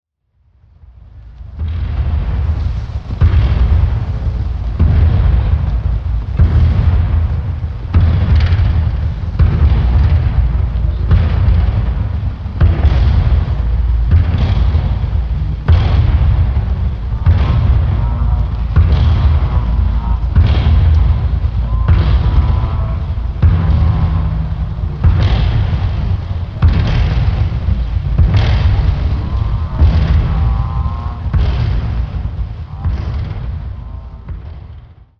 Звуки чудовища
Звук шагов приближающегося чудовища